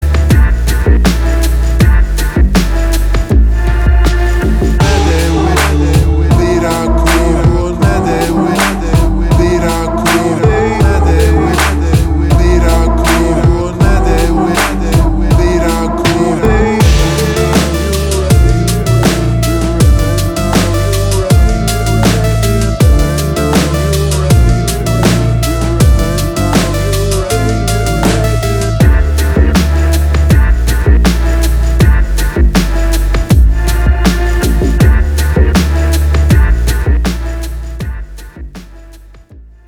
deep house
атмосферные
красивый мужской голос
спокойные
Electronica
Downtempo